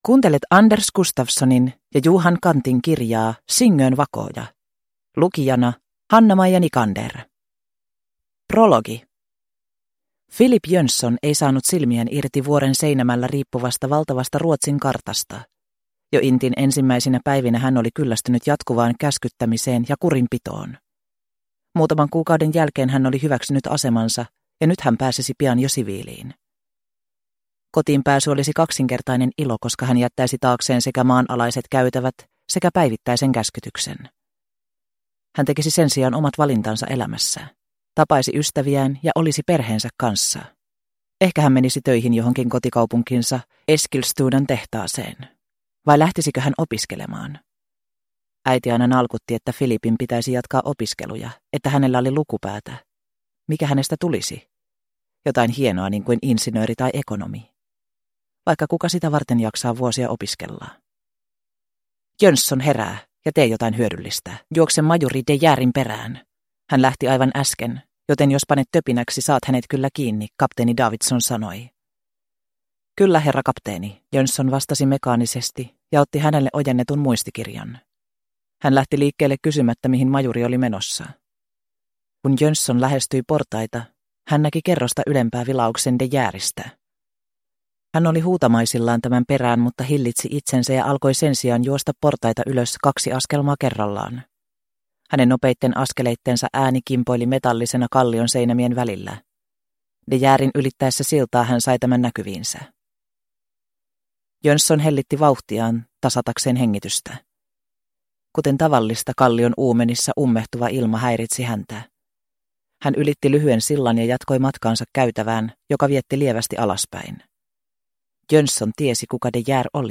Singön vakooja – Ljudbok – Laddas ner